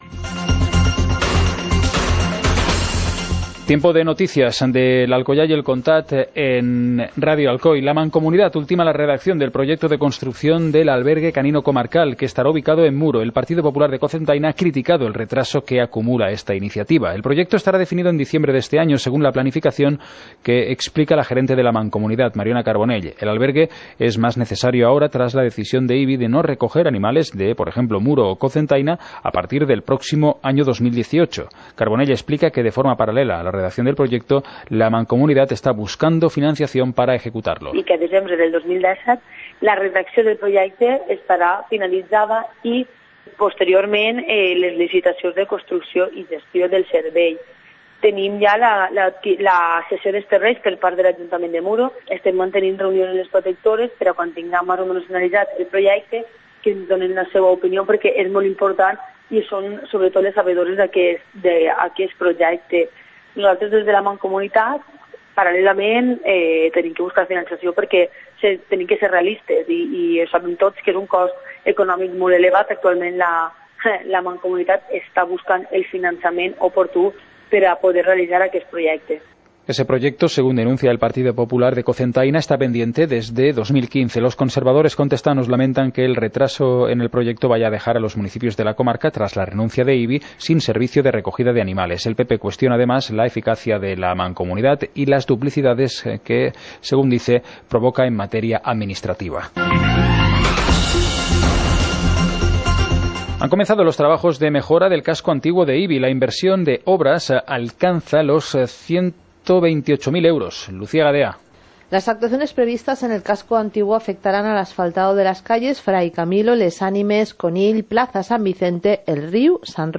Informativo comarcal - lunes, 23 de octubre de 2017